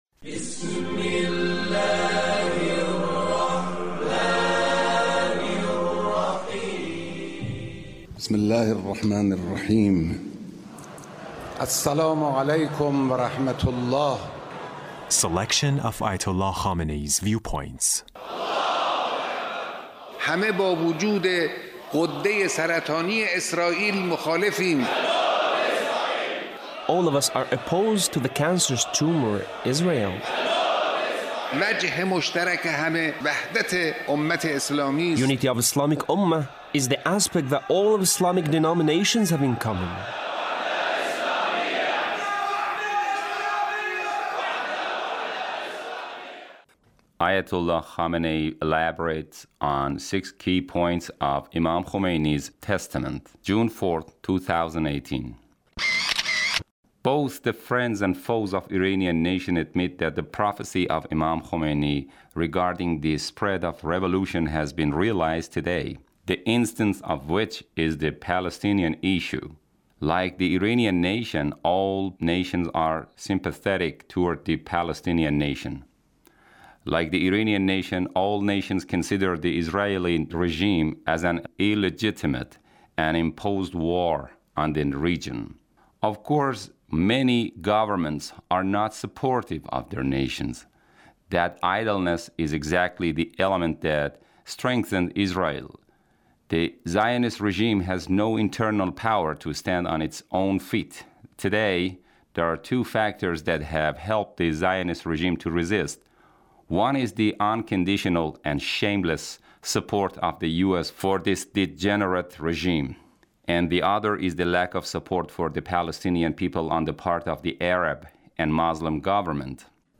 Leader's Speech about Imam Khomeini